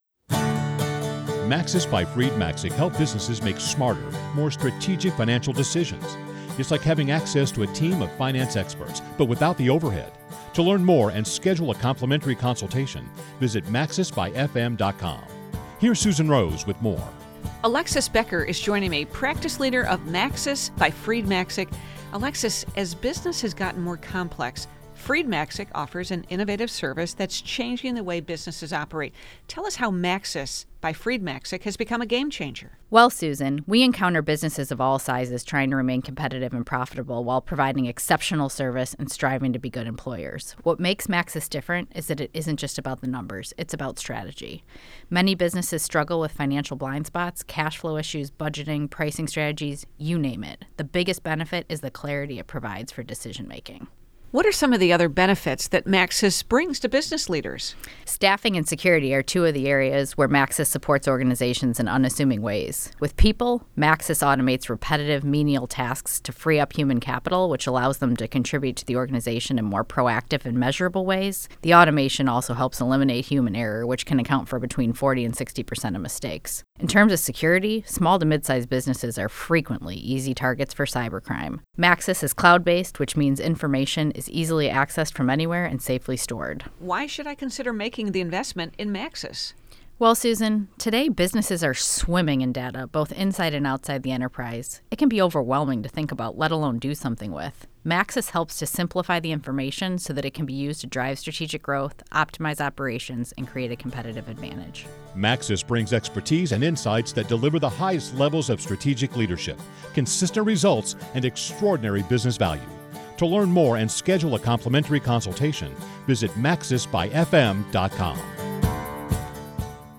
Maxis Radio Interview.mp3